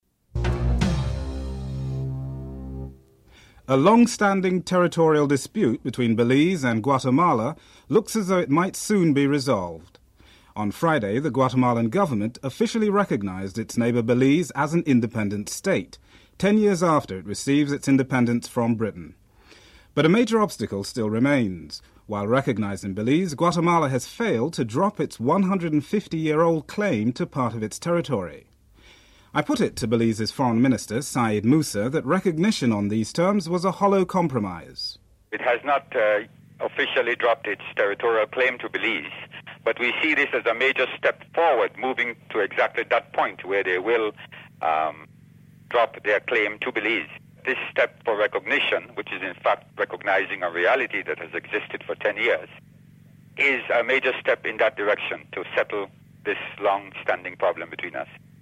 The report does not begin with a segment on headlines.